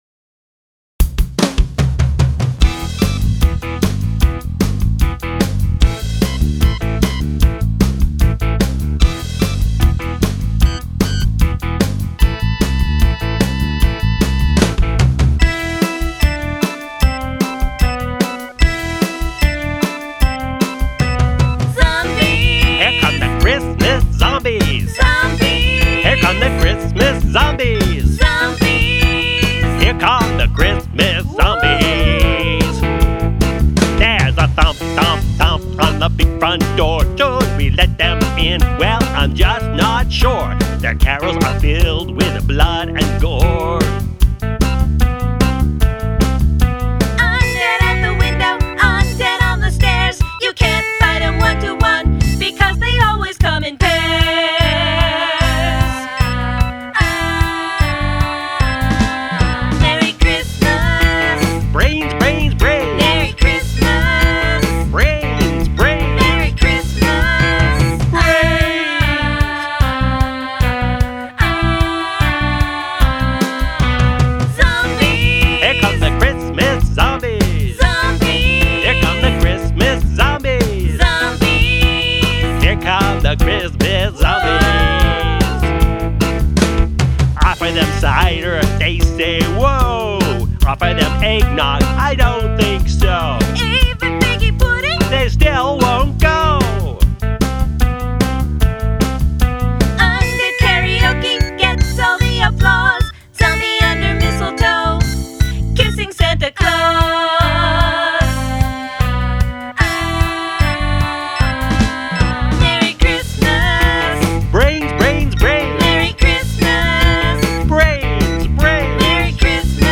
America's Premier Satiric Christmas Rock Band